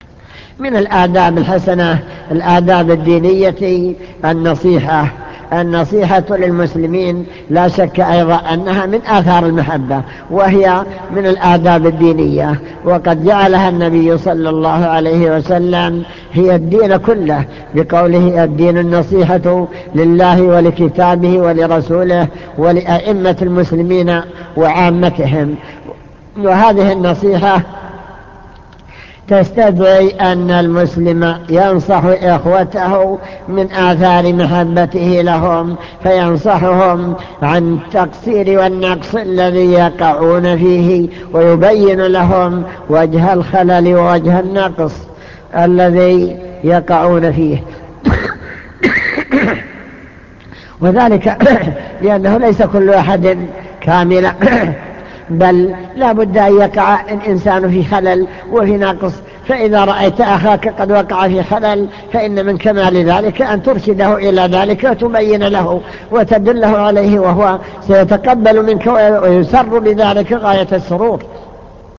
المكتبة الصوتية  تسجيلات - محاضرات ودروس  درس الآداب والأخلاق الشرعية